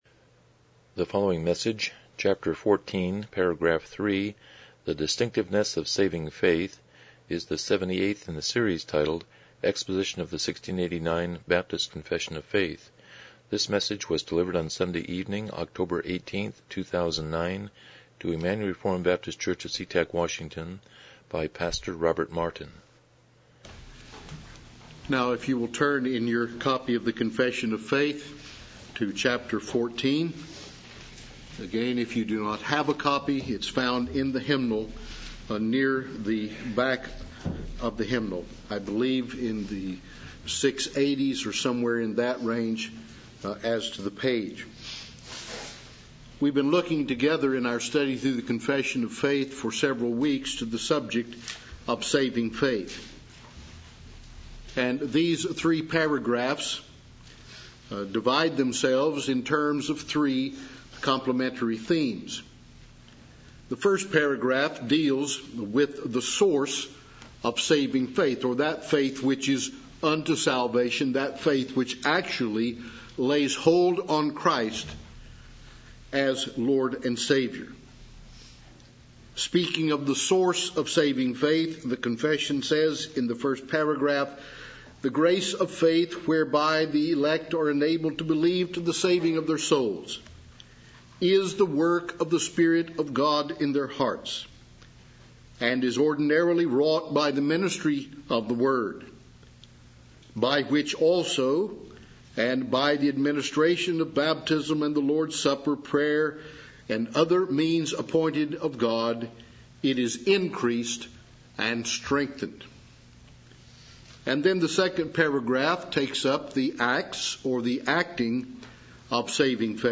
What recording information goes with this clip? Series: 1689 Confession of Faith Service Type: Evening Worship